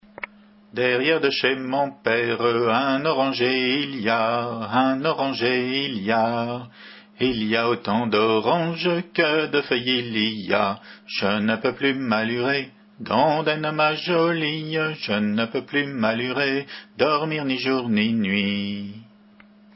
Hanter dro
Chanteurs des Pays de Vilaine
Entendu au fest-noz de Monterfil en juin 89